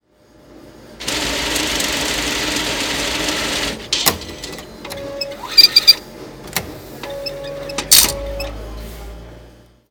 Bankomat
Sie können nun das Geld aus dem Bankomat nehmen….
bankomat